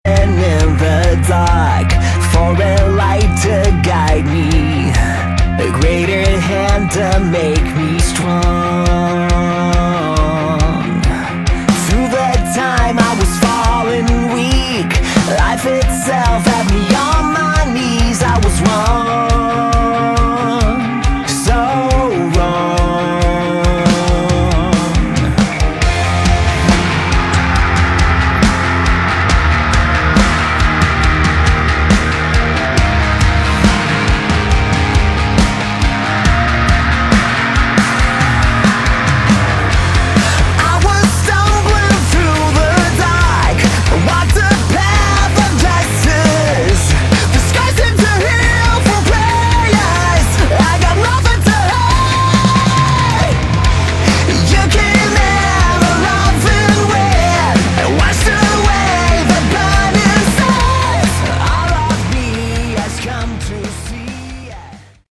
Category: Melodic Metal
Guitars, Vocals
Bass
Drums